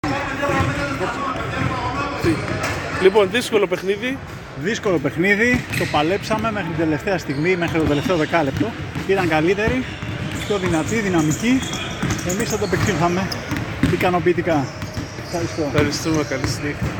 GAMES INTERVIEWS
Παίκτης OTE Cosmote